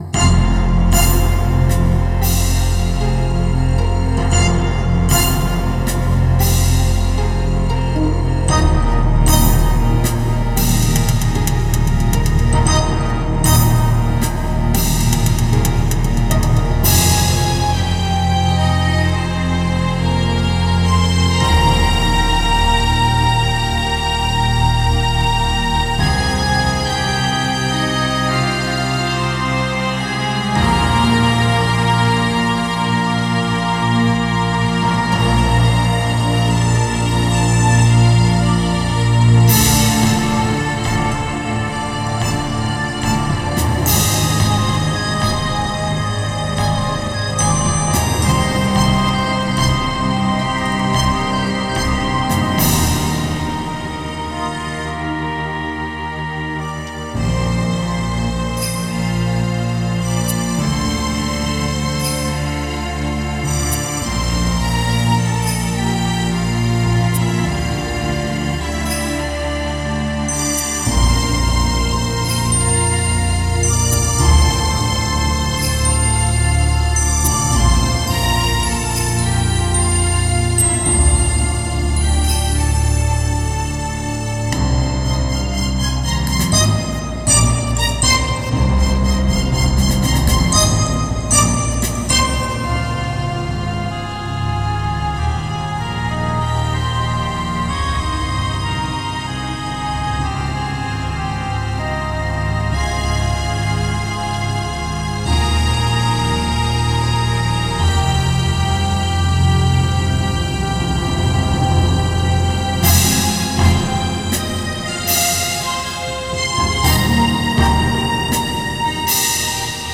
An orchestral piece. Ending credits type of theme.